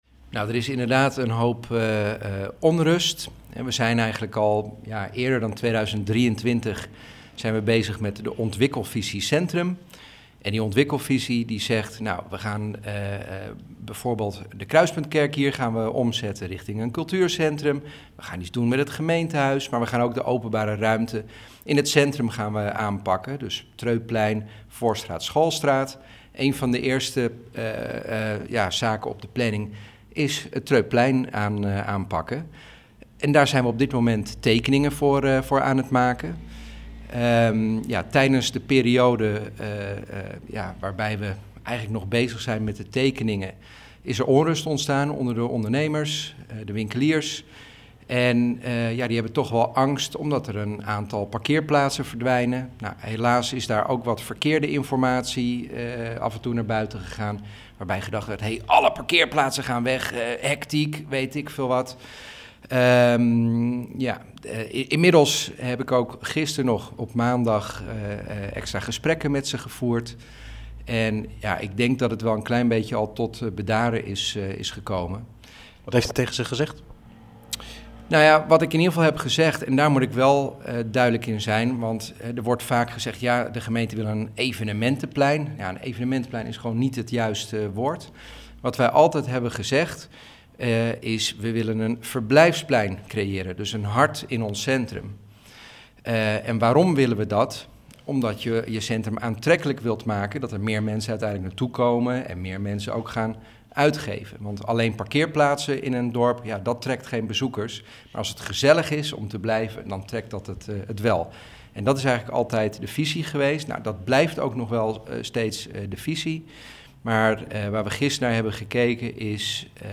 Verslaggever
in gesprek met wethouder Paul de Bruijn